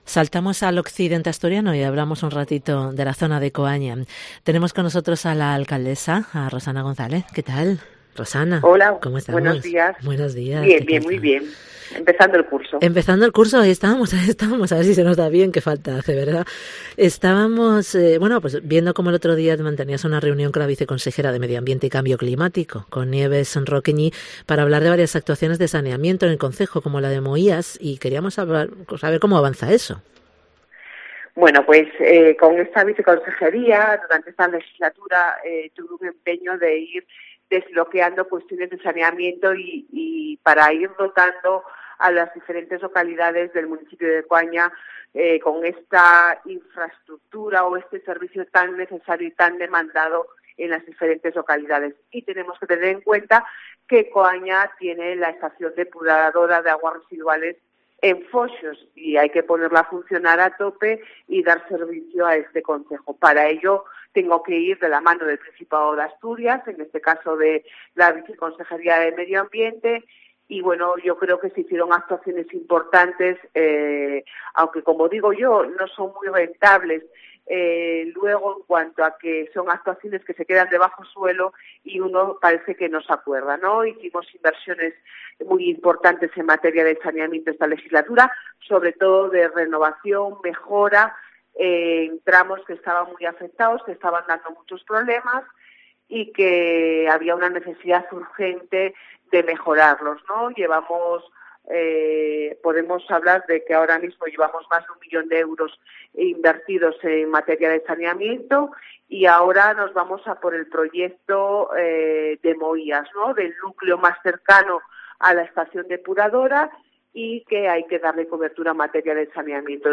Rosana González, alcaldesa de Coaña, informa sobre los proyectos de saneamiento y abastecimiento